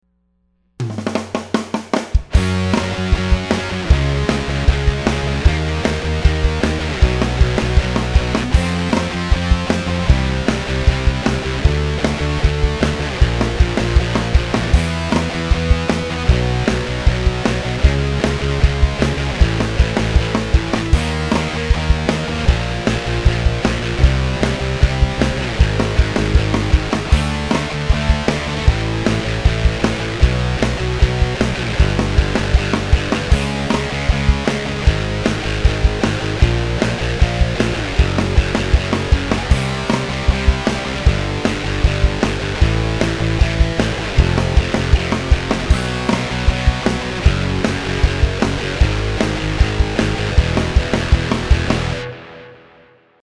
Power Chord Drill III play along track.
Power-Chord-Drill-III.mp3